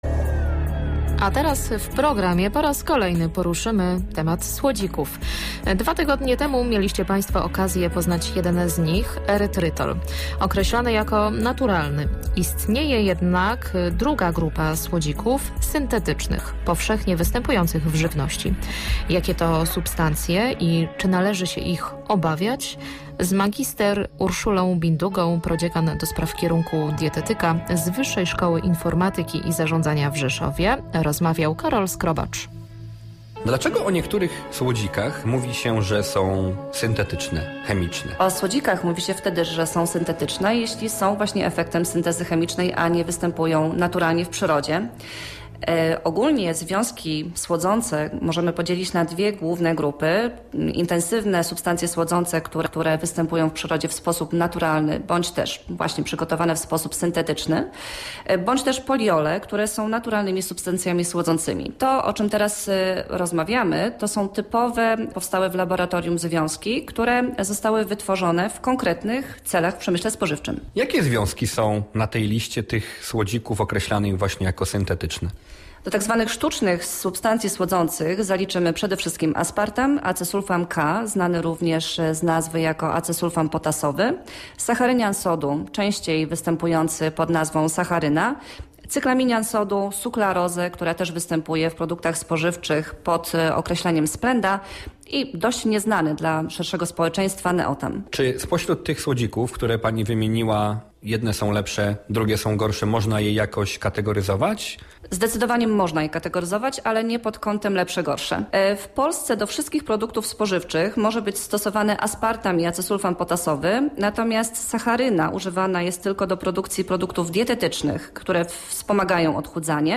Science – radiowe odkrycia – rozmowa